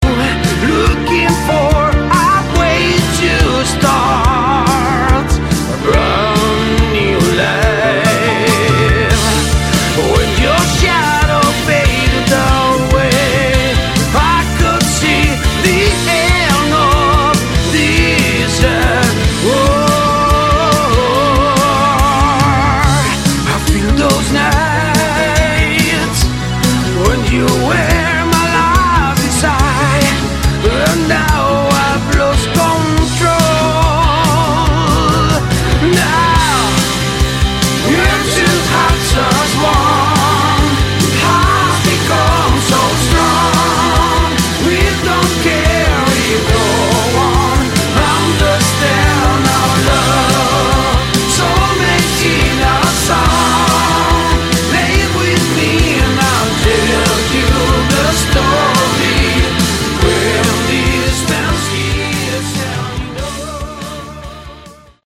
Category: Melodic Rock
guitar
vocals
keyboards
drums
bass